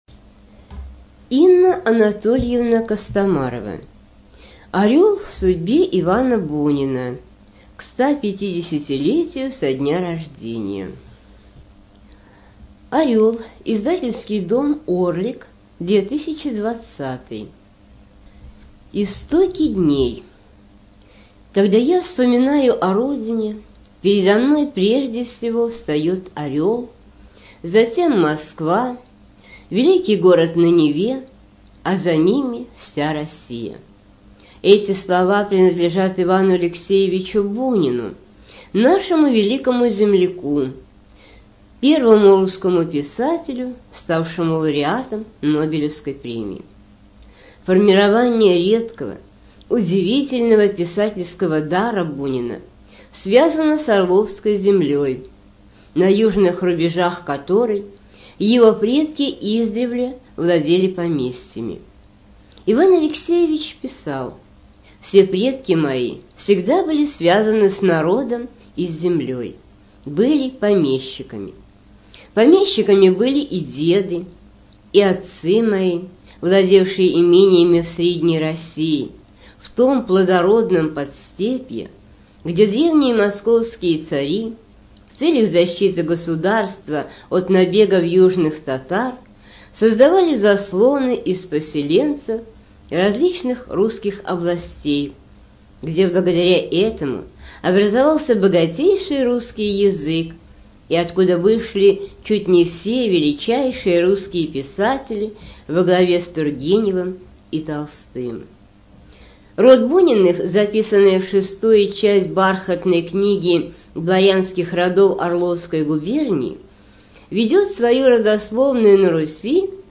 Студия звукозаписиОрловская областная библиотека для слепых